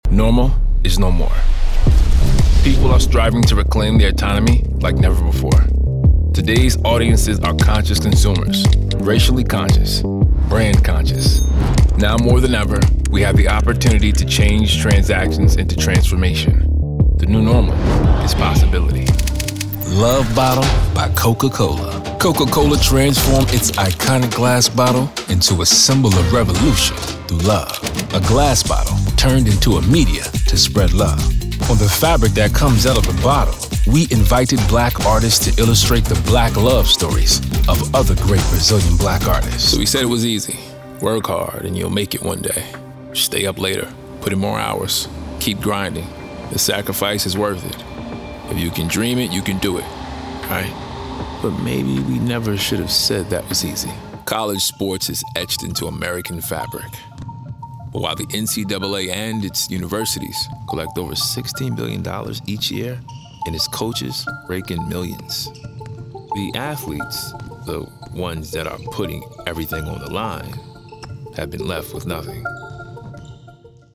Full-time VO talent offering a home studio and quick project turnaround.
NARRATION-DEMO
Young Adult
Middle Aged
NARRATION-DEMO.mp3